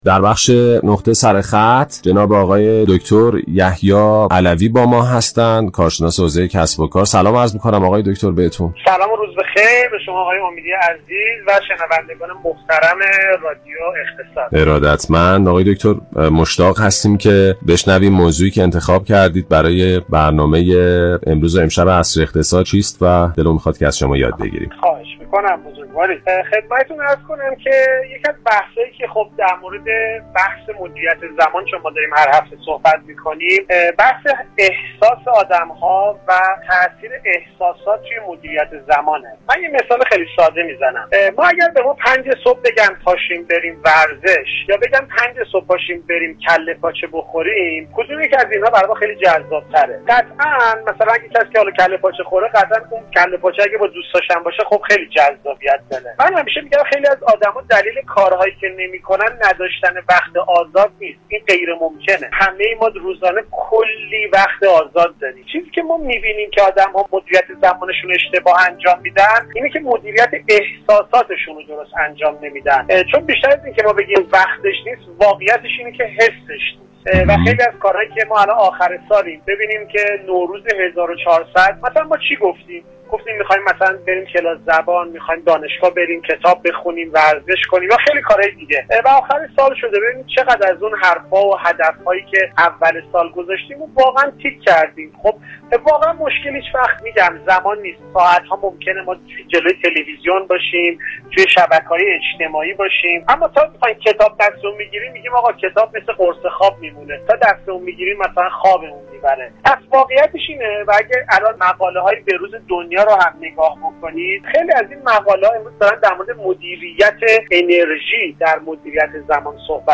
با رادیو اقتصاد برنامه ی نقطه سره خط (عصر اقتصاد)